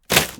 fishpole_break.ogg